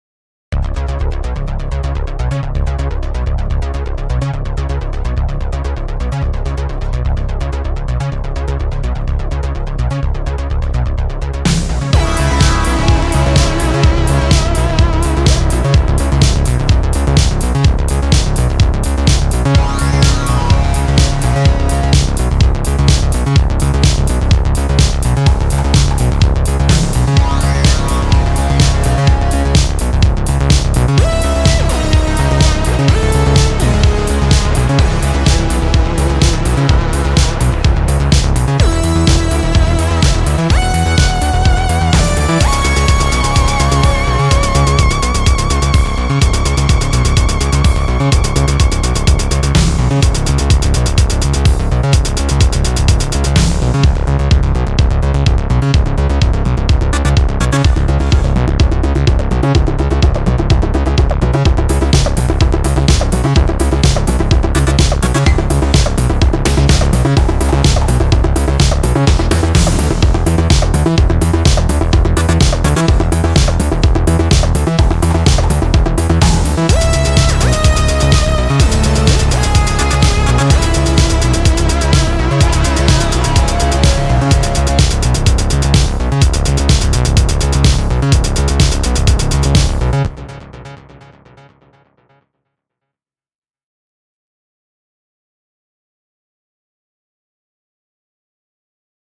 Indietronica